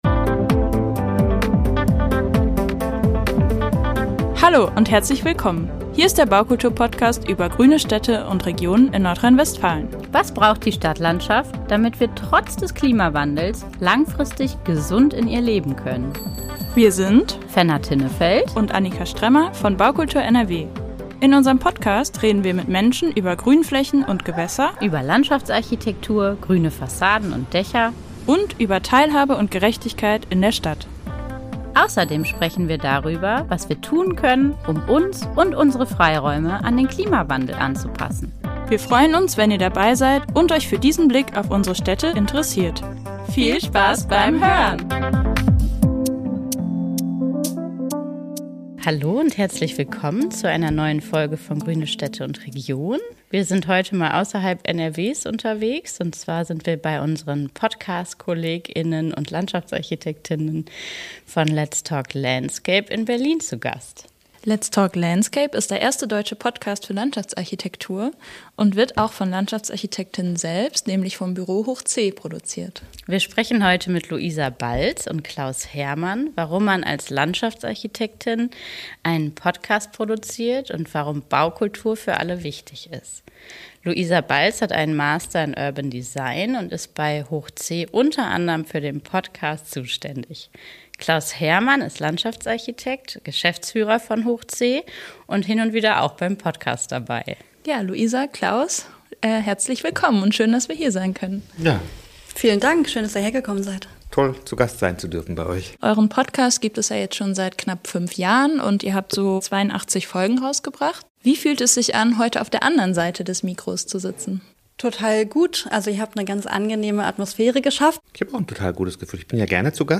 Wie lässt sich Landschaftsarchitektur verständlich vermitteln? Ein Gespräch